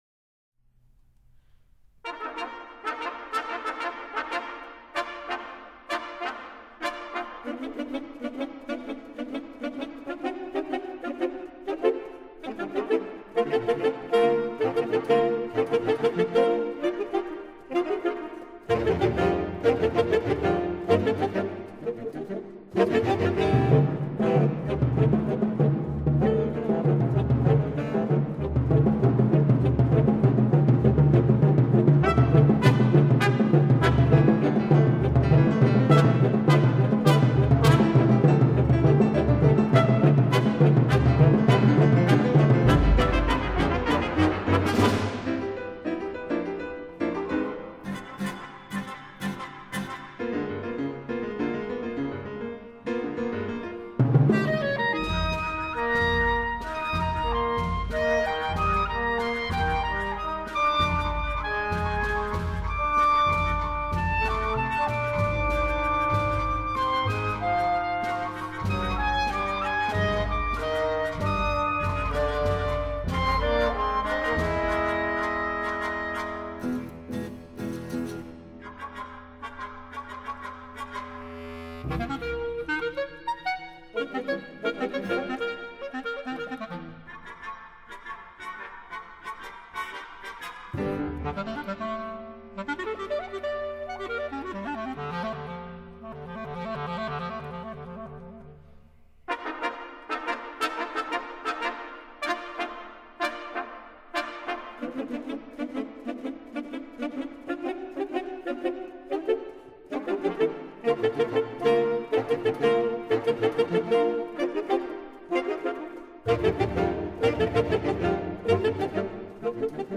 没听过黑管搞爵士吧，不比萨克斯差哦 01 - 03.
for clarinet & jazz band 04